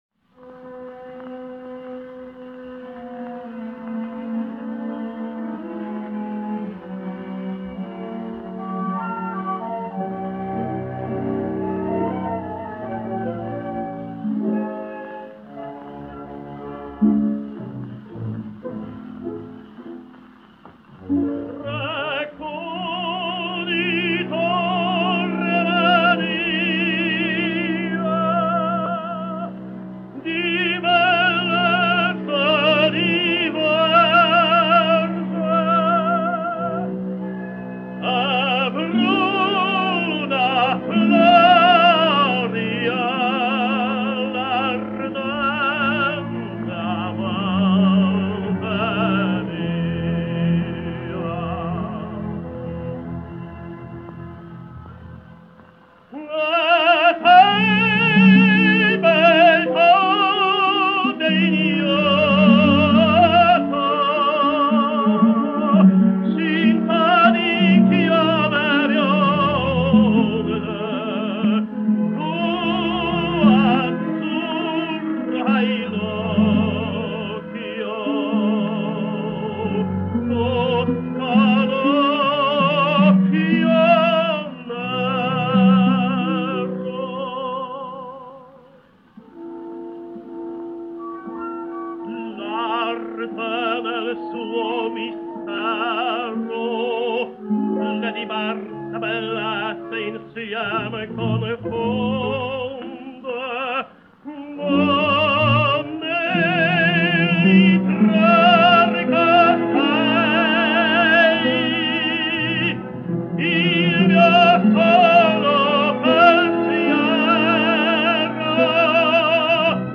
słynny tenor